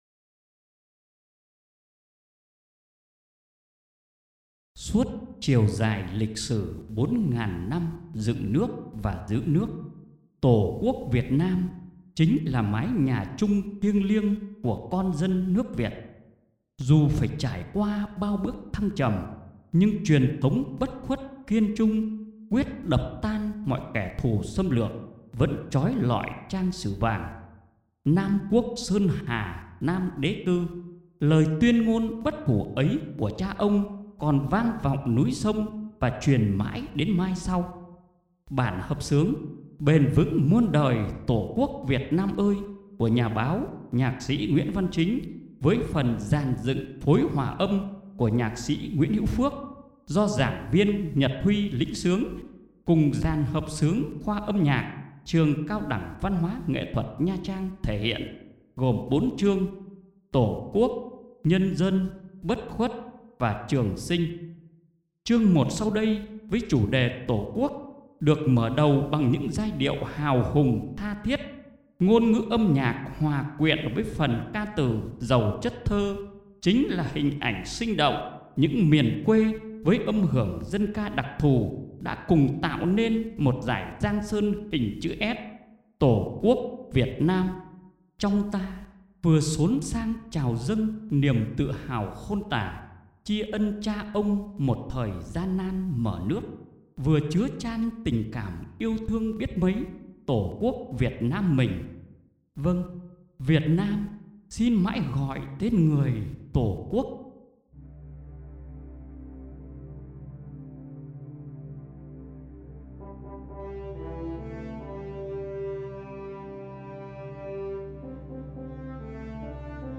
Xin bấm vào đây để nghe hợp xướng :